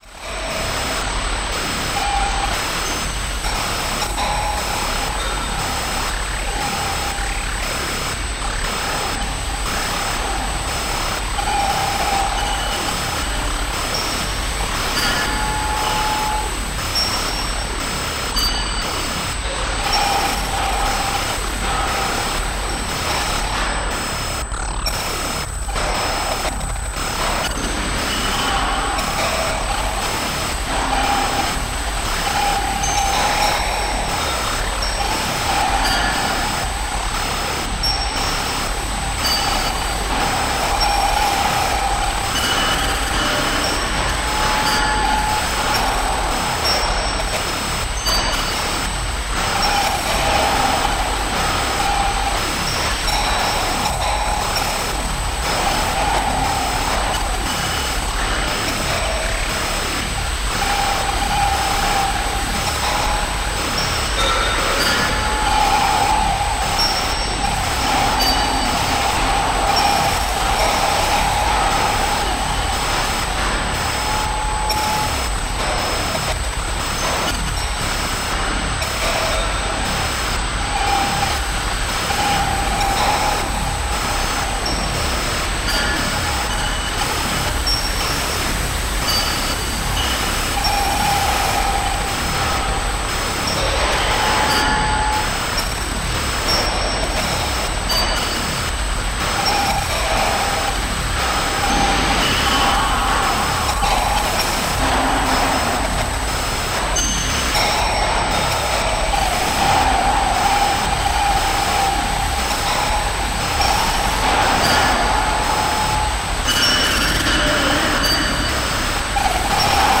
dark atmospheric electronics